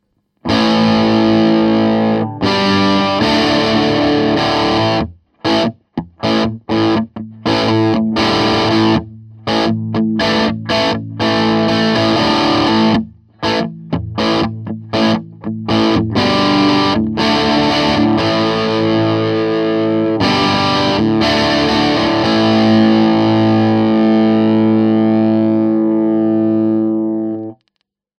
Crunch – Rock, Grunge, Crossover, Blues
Der Sound wird »breiter«, flächiger, gepresster, ist dabei aber noch längst nicht so kratzend oder beißend wie beim High-Gain.
Hier heben sich die Transienten schon deutlich weniger vom Sustain ab, die Dynamik ist reduziert, die Kompression spürbar stärker.
classic_rock_.mp3